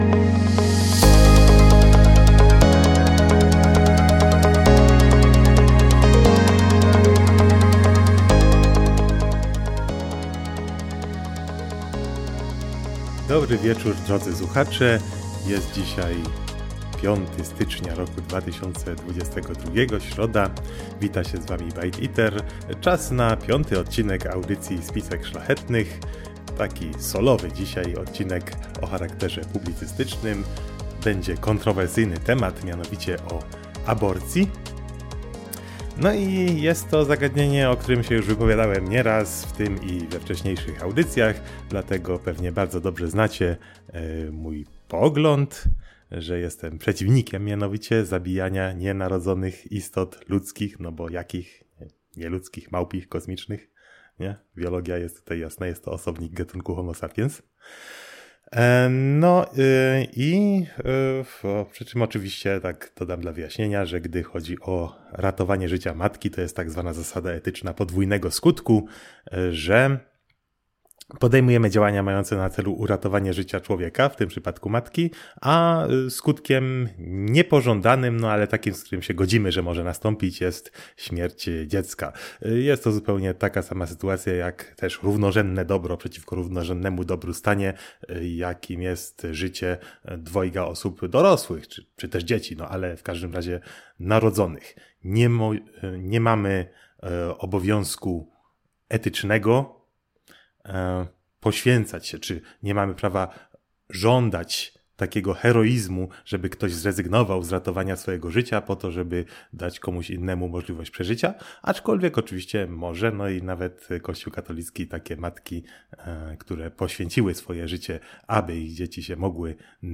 Przyjdź posłuchać, porozmawiać i pokomentować Spisek szlachetnych na żywo we wtorki w radiu Enklawa.